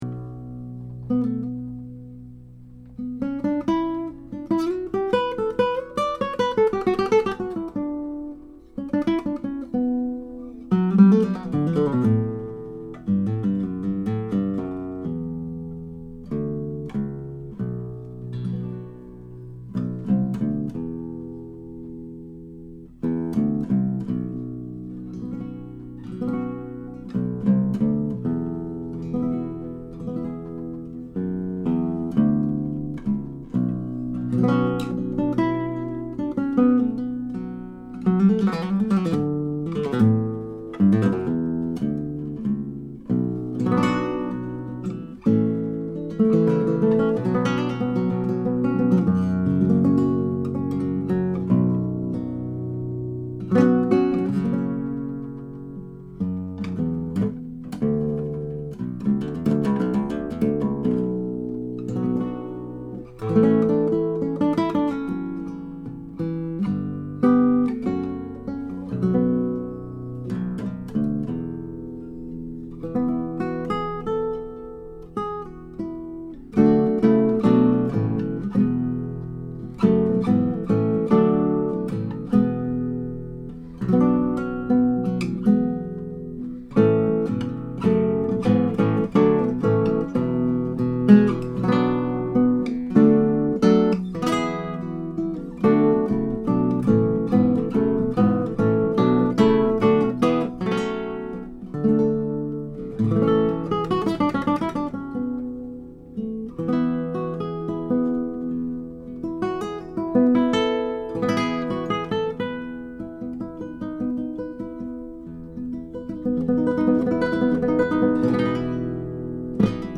2009 Bartolex 8-String Classical Harp Guitar
This guitar has a master-grade solid Cedar top, laminated East Indian Rosewood back & Sides, and creates a beautifully-balanced Spanish sound with crisp trebles, deep basses, and excellent resonance and sympathetic sustain.
I have the guitar tuned to a standard 8-string tuning: 1-6 is normal, and the 2 low strings are 7=D 8=A. Strings By Mail has sells standard 8-string sets and can put together custom sets for you at a discount over individual strings.
This is straight, pure signal with no additional EQ or effects.
Improv3.mp3